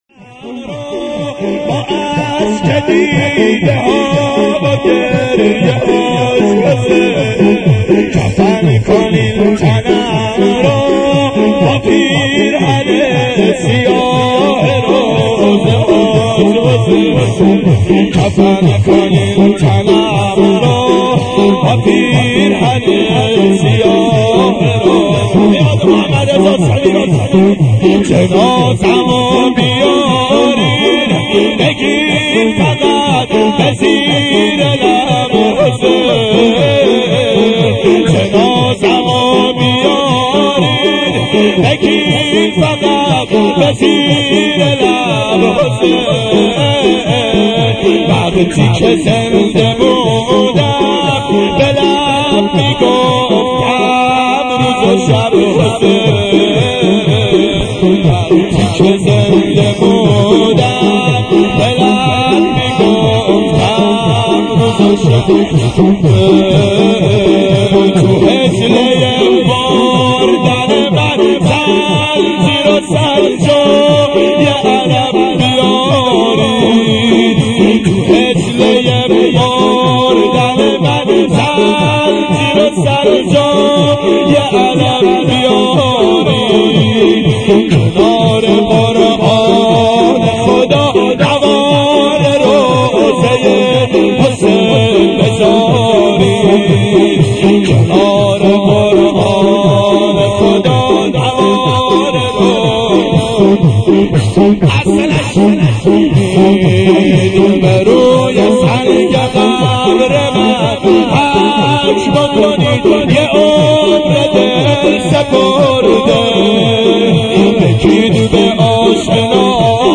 گلچین مداحی